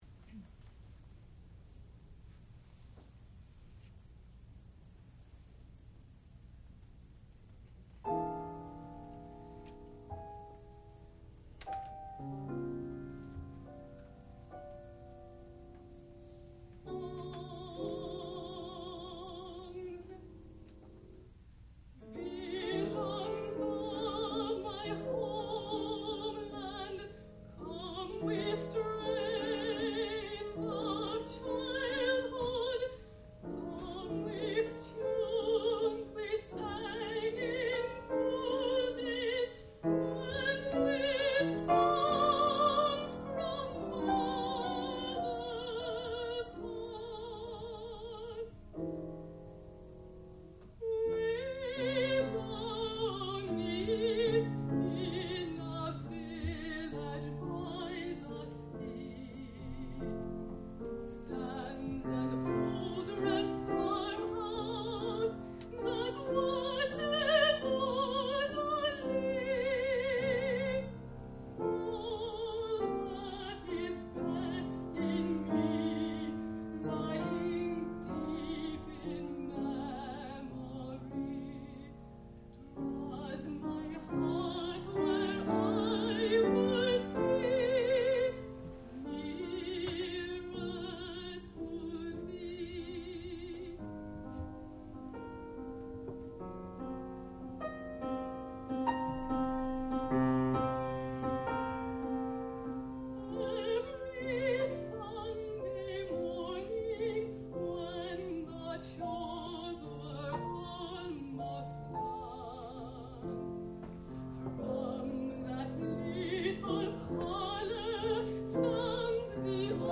sound recording-musical
classical music
piano
mezzo-soprano
Master's Recital